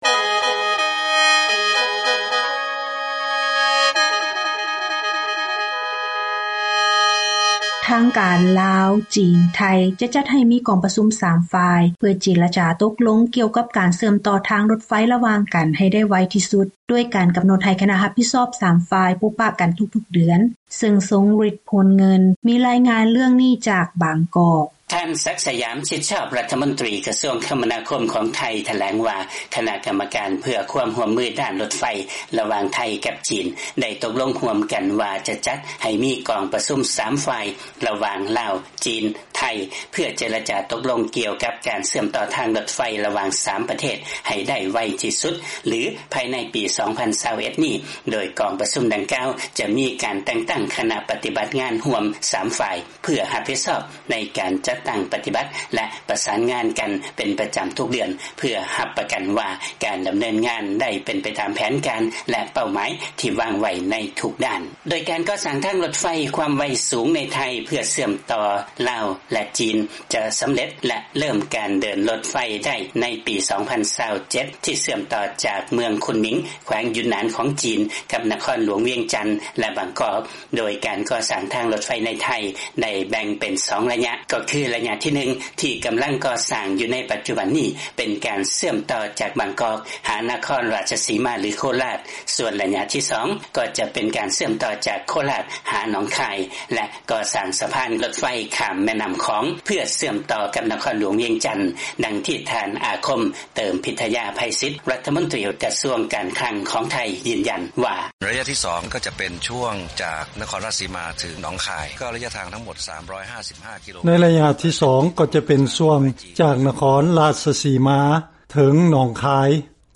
ຟັງລາຍງານ ທາງການ ລາວ, ຈີນ, ໄທ ຈະຈັດໃຫ້ມີກອງປະຊຸມ 3 ຝ່າຍເພື່ອເຈລະຈາຕົກລົງ ກ່ຽວກັບ ການເຊື່ອມຕໍ່ທາງລົດໄຟ